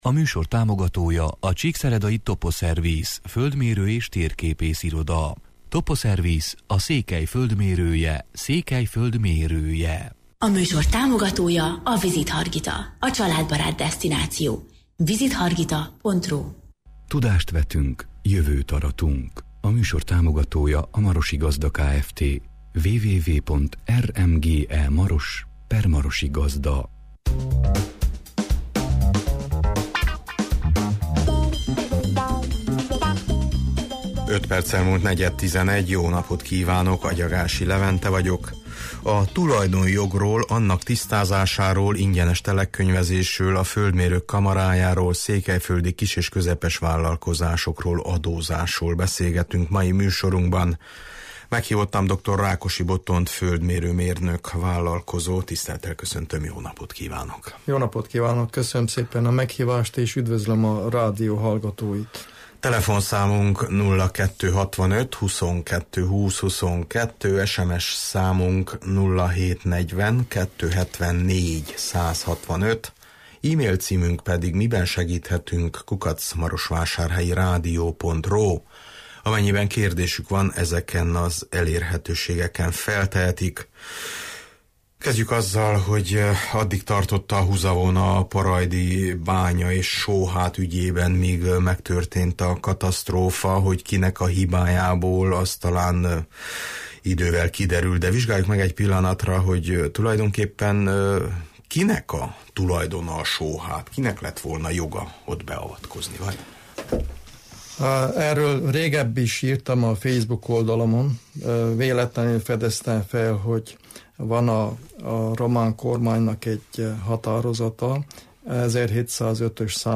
A tulajdonjogról, annak tisztázásáról, ingyenes telekkönyvezésről, a földmérők kollégiumáról, székelyföldi kis- és közepes vállalkozáskról, adózásról beszélgetünk mai műsorunkban.